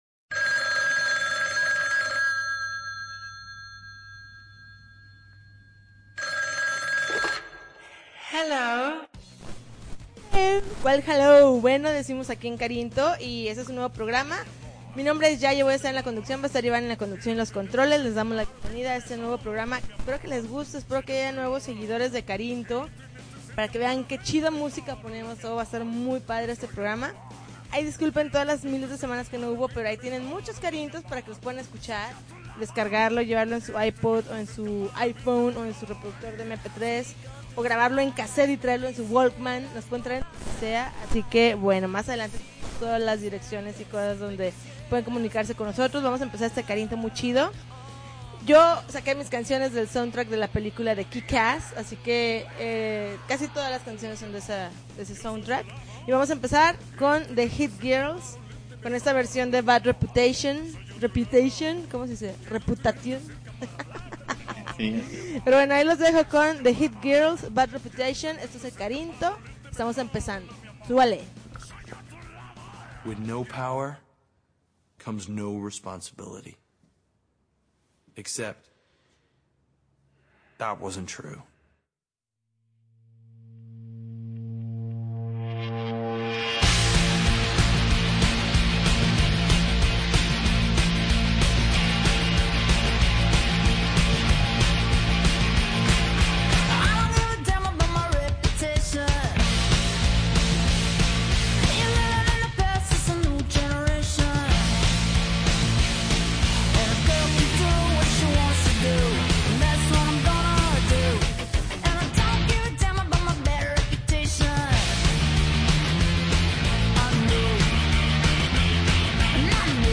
July 25, 2010Podcast, Punk Rock Alternativo